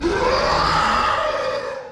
mgroan12.mp3